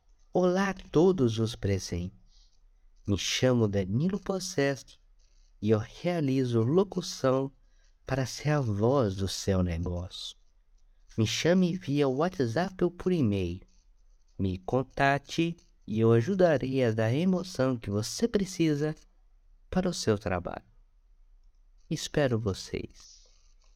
MEU TRABALHO COMO LOCUTOR COMERCIAL